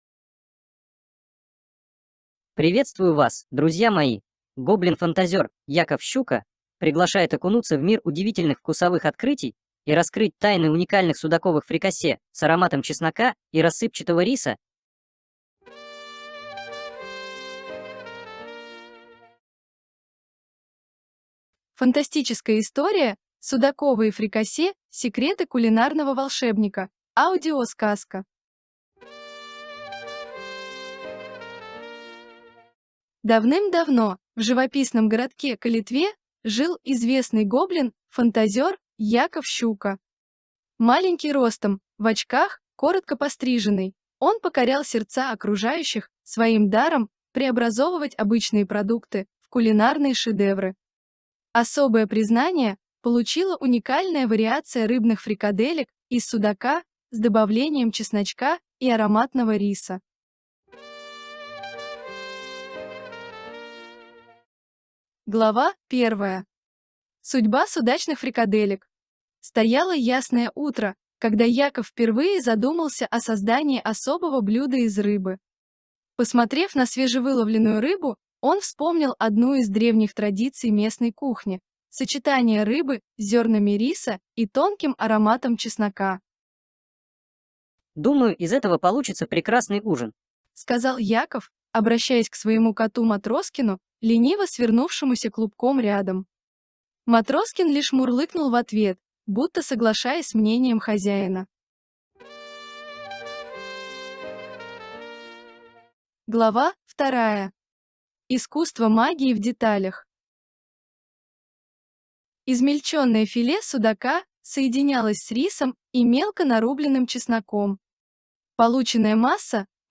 Аудиосказка